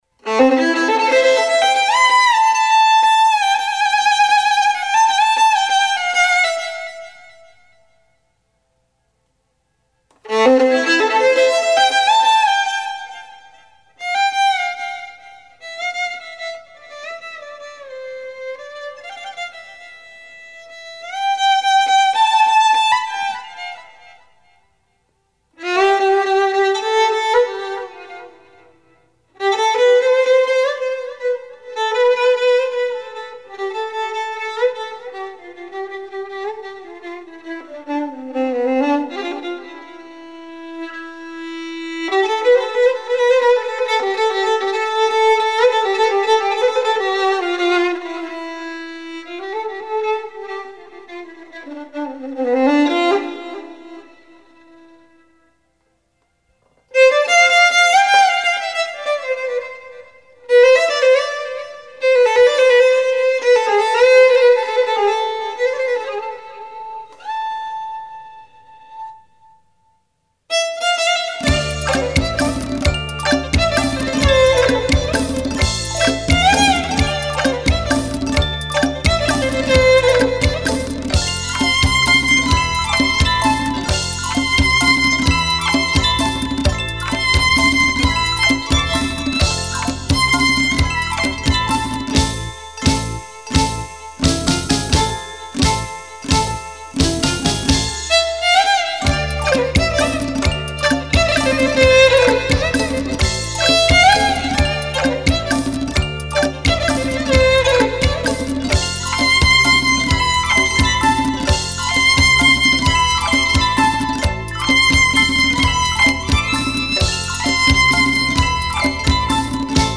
آهنگ بی کلام ملایم و رمانتیک ورود به تالار: